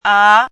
chinese-voice - 汉字语音库